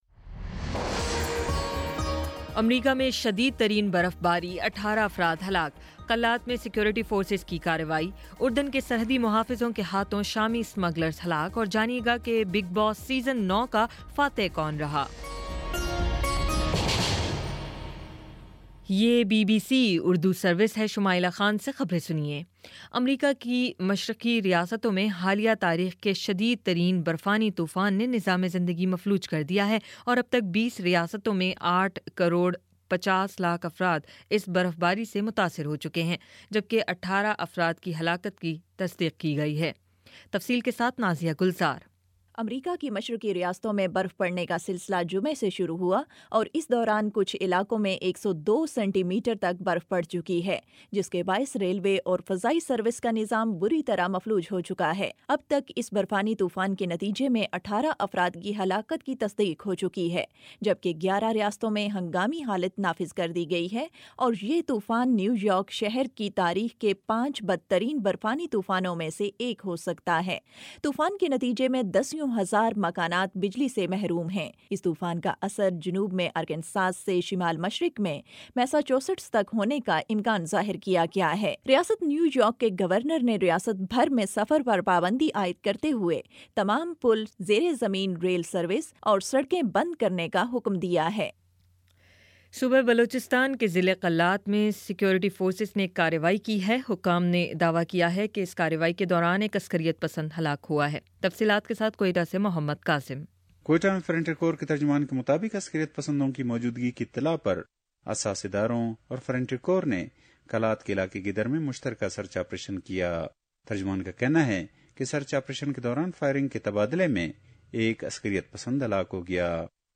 جنوری 24 : شام پانچ بجے کا نیوز بُلیٹن